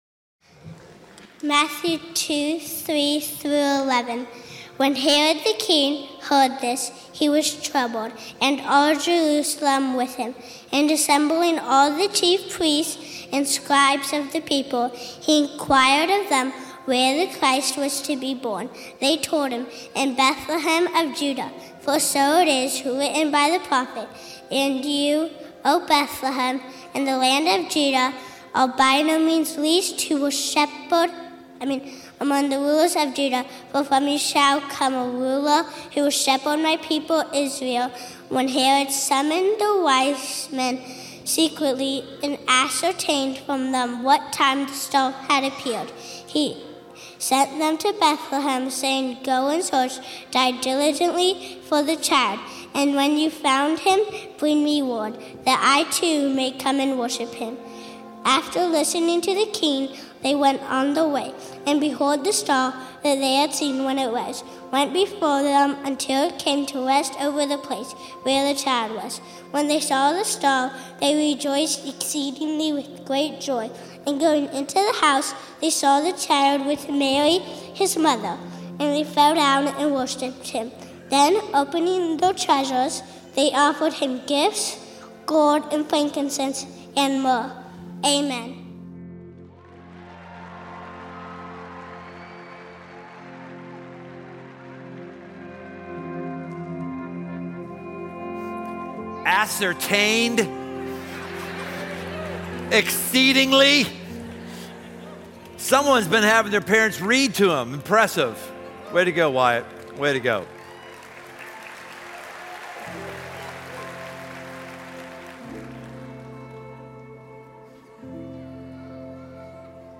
Live music and a look at the Christmas story like you’ve never seen it before.